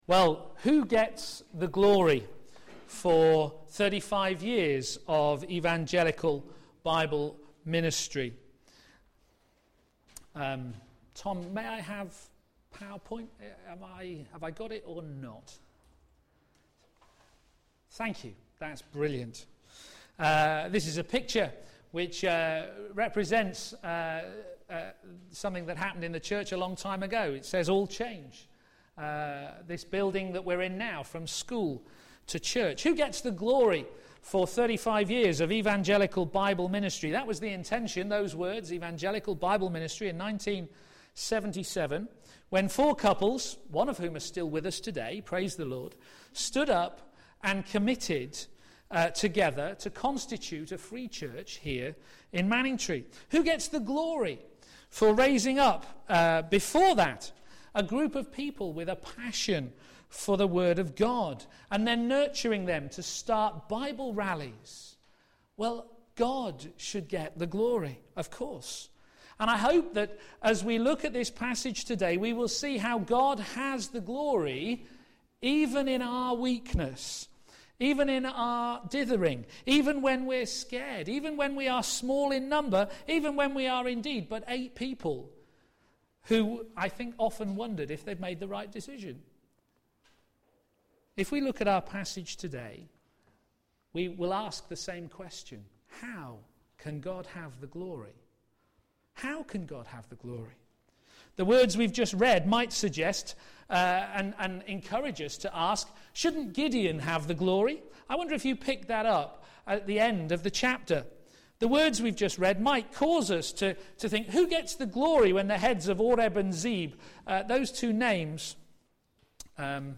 God Will Have the Glory Sermon